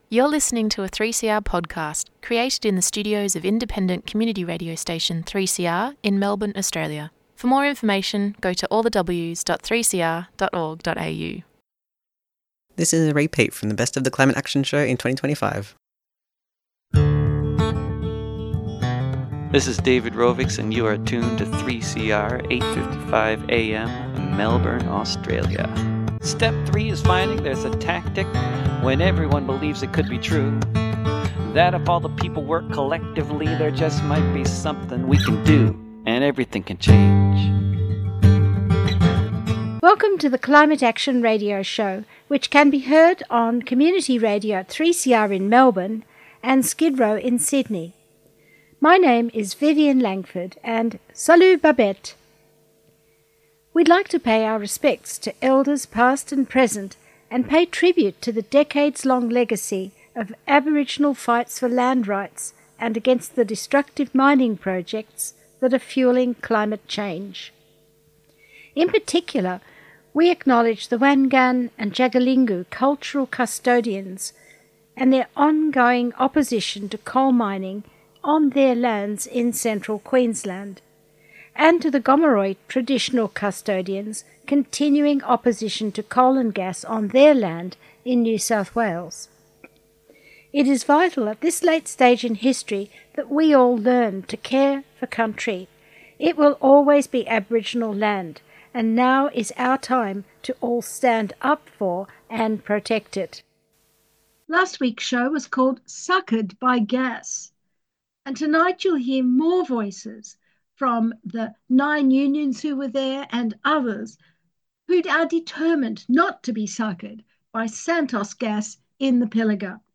This podcast contains the voices of Gomeroi people and their allies who cames down from Narrabri and elsewhere to bring a sacred flame to NSW Parliament.